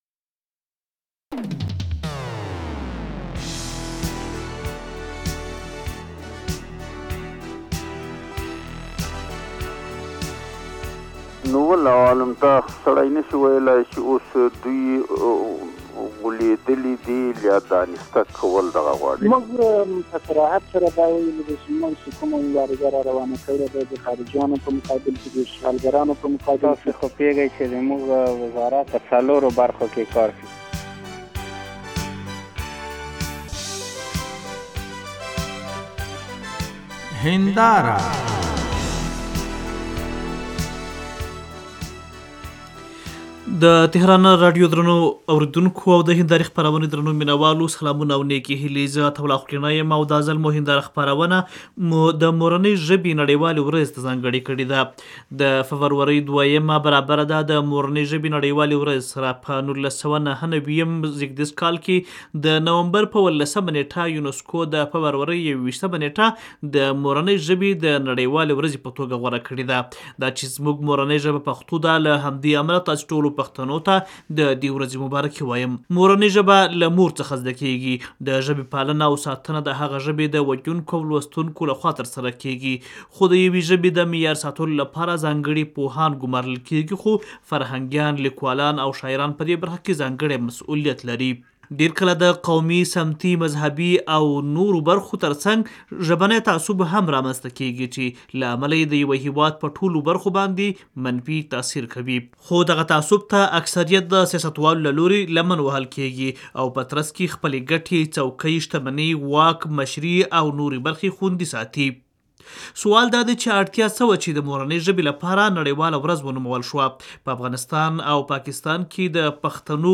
د فبرورۍ دویمه برابرده ده، د مورنۍ ژبې نړیوالې ورځې سره، په ۱۹۹۹ز کال د نومبر په اولسمه نېټه يونسکو، د فبرورۍ يو وېشتمه نېټه د مورنۍ ژبې د نړيوالې ورځې په توګه غوره کړې، موږ هم د تهران راډیو هینداره خپرونه د مورنۍ ژبې اړوند بحث ته ځآنګړې کړې.